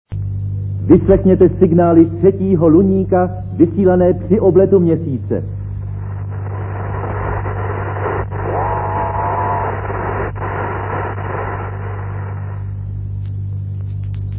Il faut garderer à l'esprit que la plupart des enregistrements sont anciens.